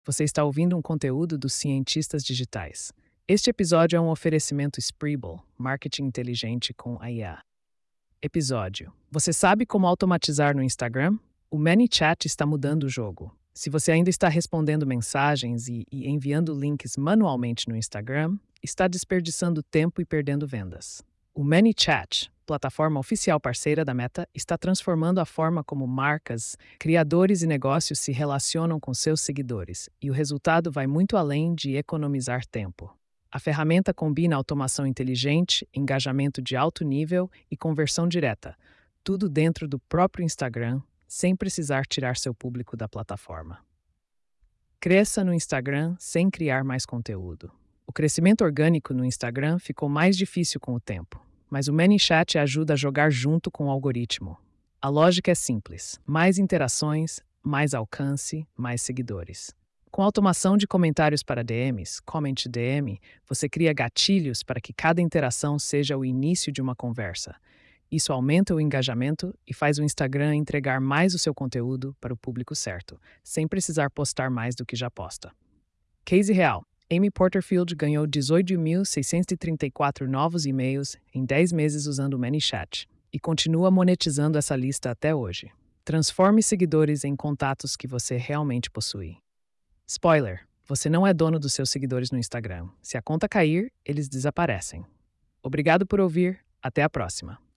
post-4201-tts.mp3